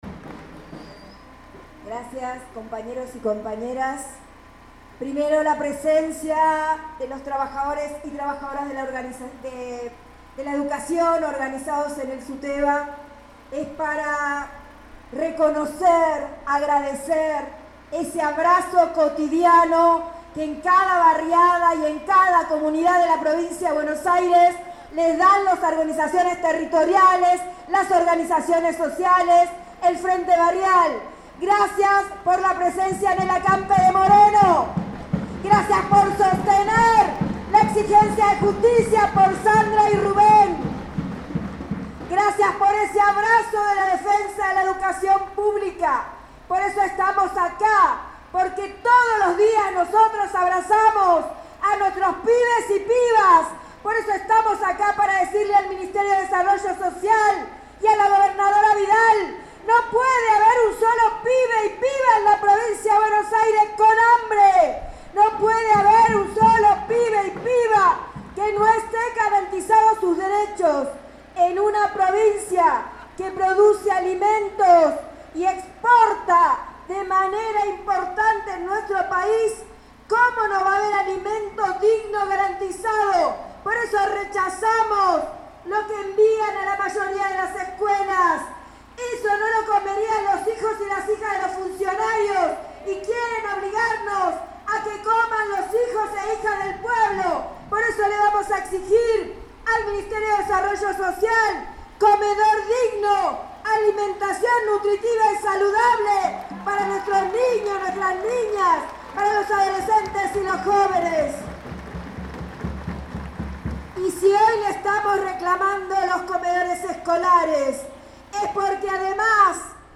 Jornada de lucha del Frente Barrial CTA en la ciudad de La Plata